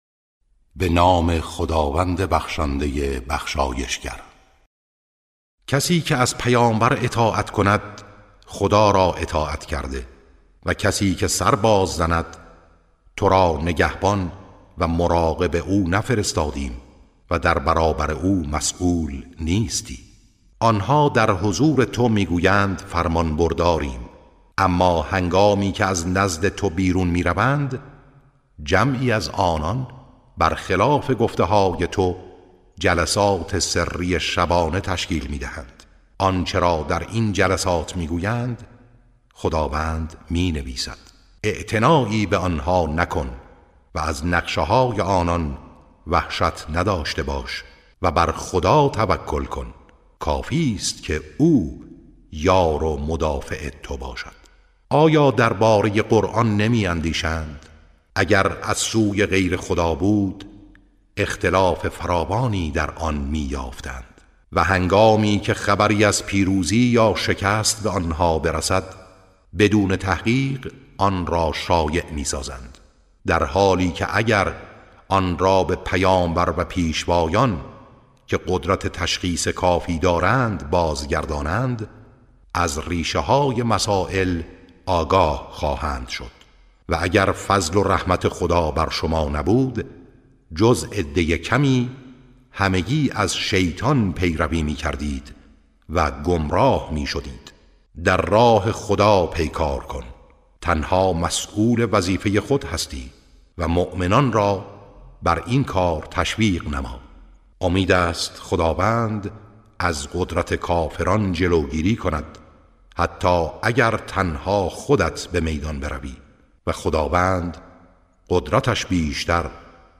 ترتیل صفحه 91 از سوره نساء(جزء پنجم)